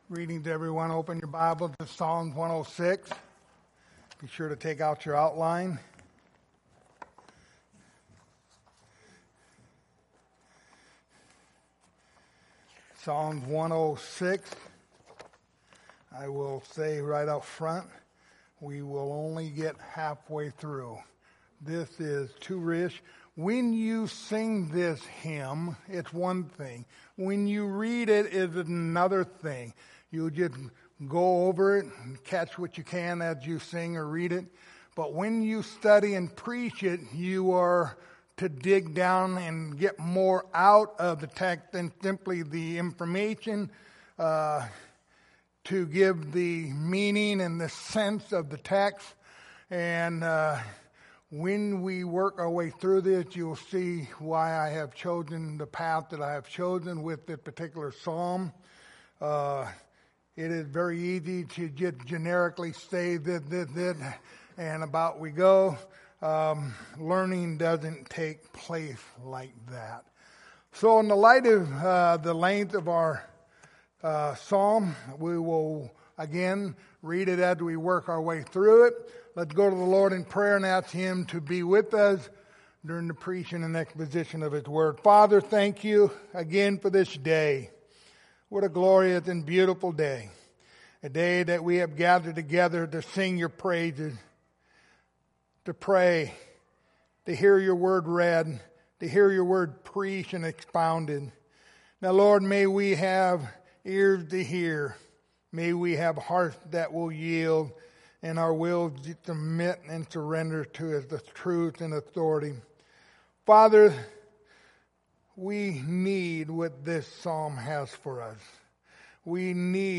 Passage: Psalms 106:1-46 Service Type: Sunday Morning